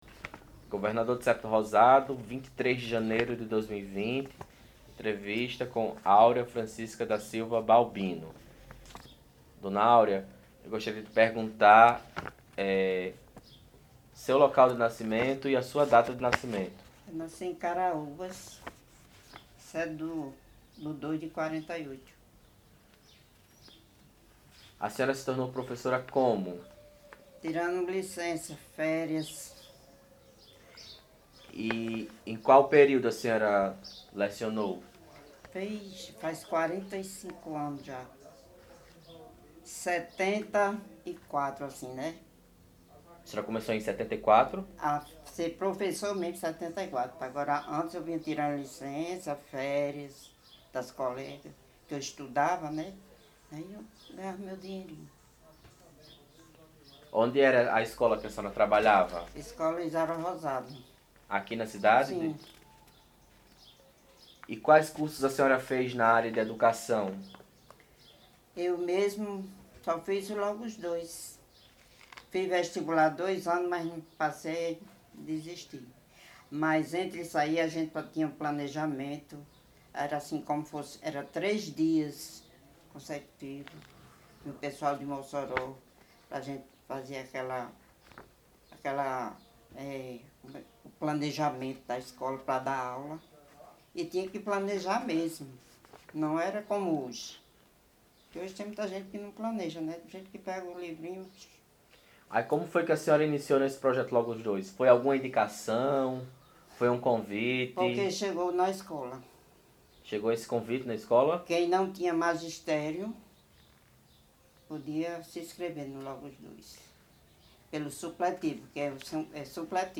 Projeto: Acervo Trajetórias Docentes Tipo: entrevista temática